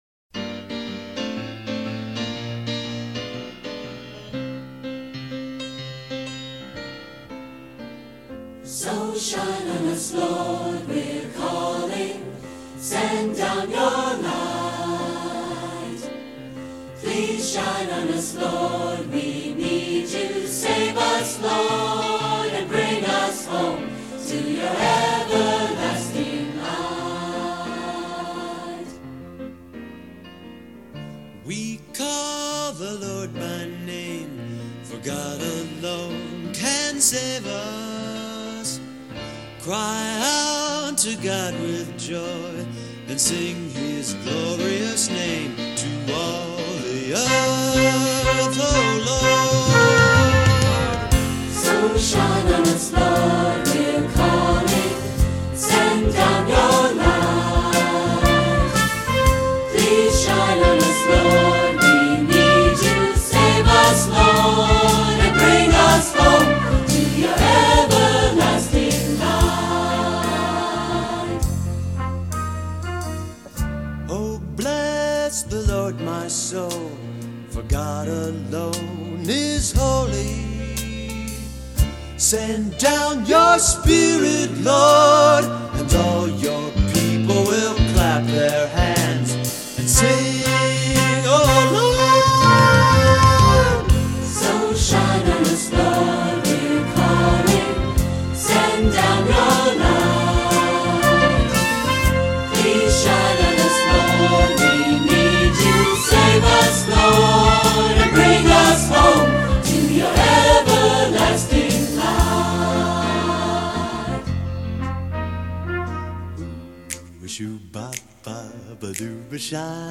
Voicing: Assembly,SATB,Soloist or Soloists